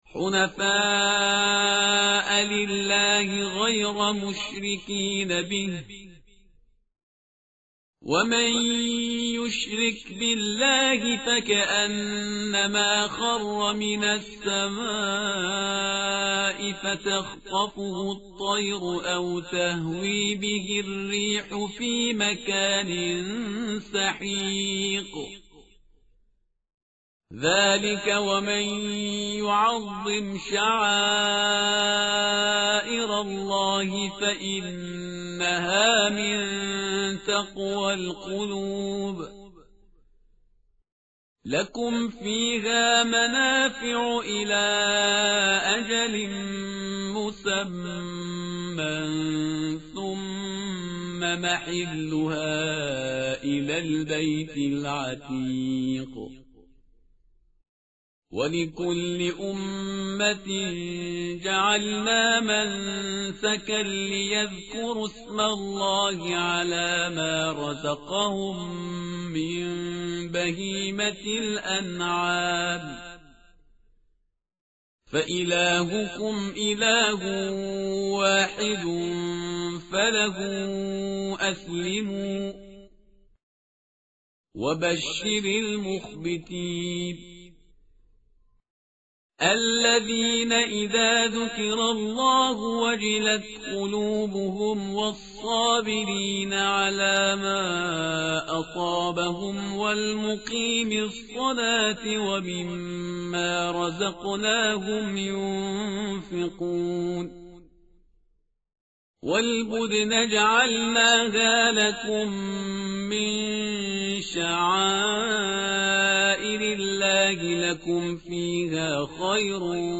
tartil_parhizgar_page_336.mp3